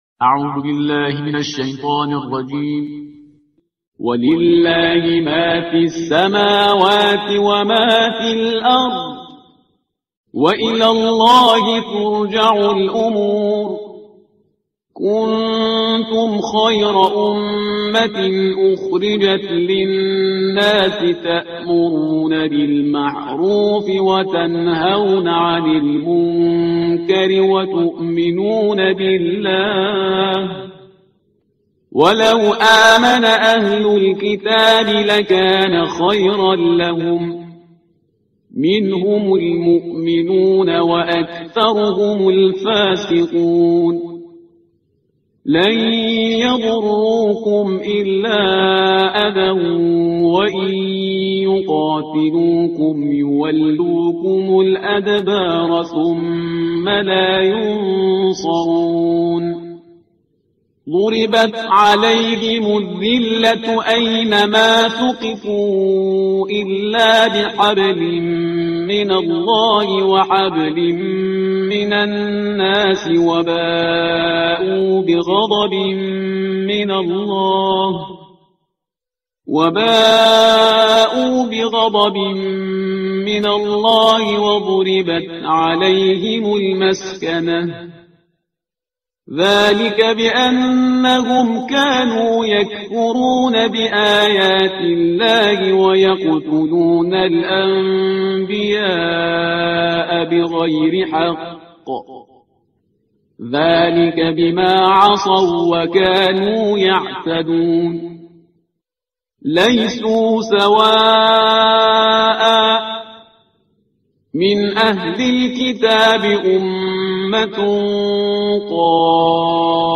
ترتیل صفحه 64 قرآن با صدای شهریار پرهیزگار